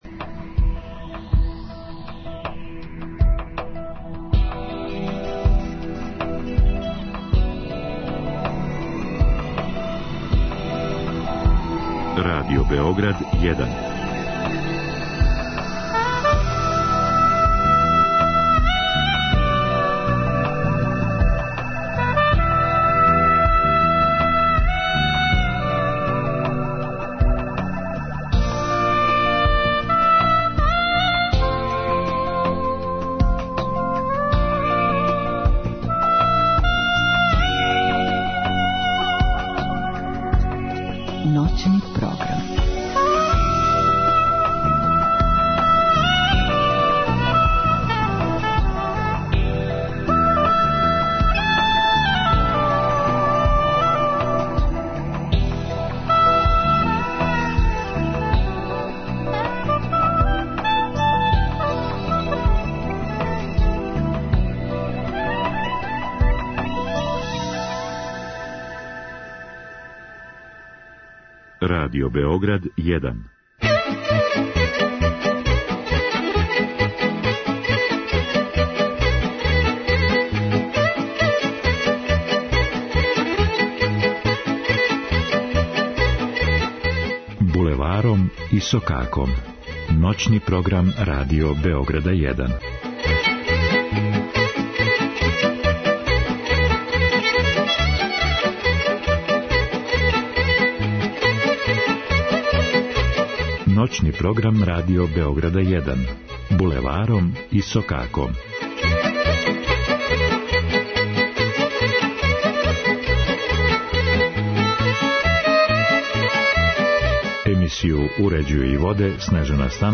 изворну, староградску и компоновану музику у духу традиције